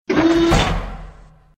cannon-enter.ogg